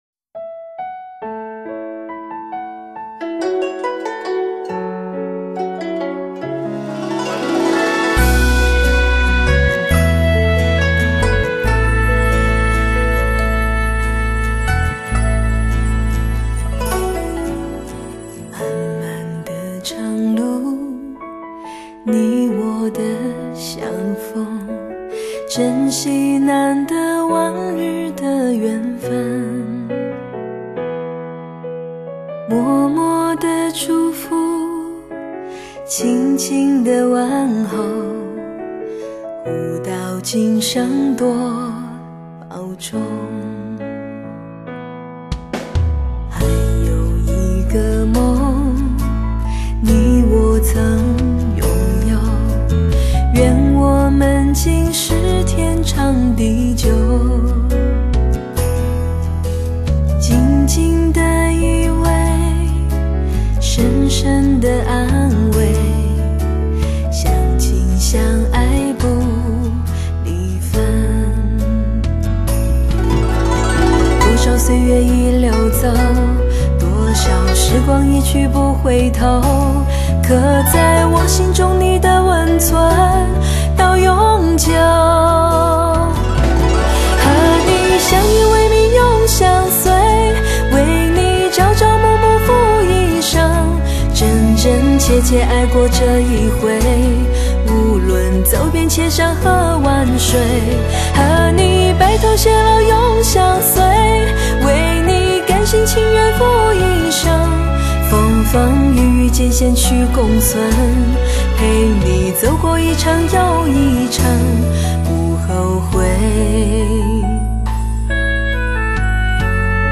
共同营造恬静和谐、略带冥想意味又具幻想性诗意的氛围，陶醉油然而生，从心底而来的温暖和幸福久久不会离去。